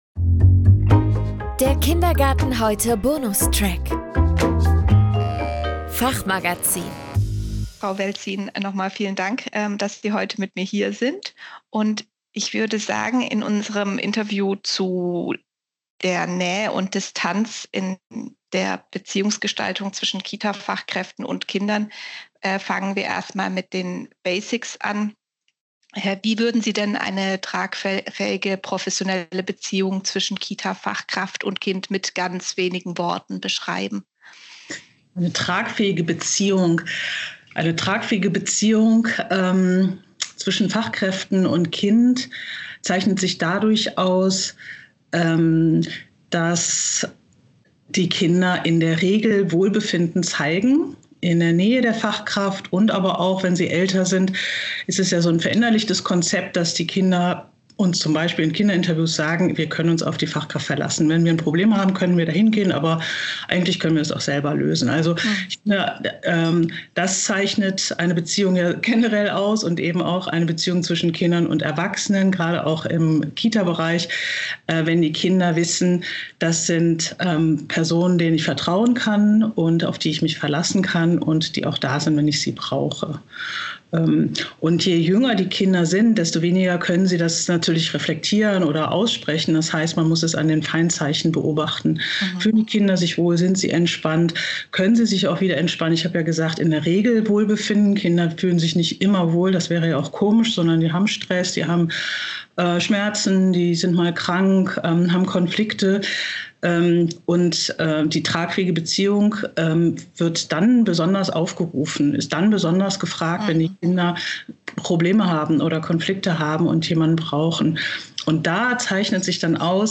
Doch was tun bei vermeidenden, zurückhaltenden Kindern oder Kindern, die extrem viel Nähe suchen? Ein spannendes Gespräch über Bindung, Emotionen und persönliche Grenzen.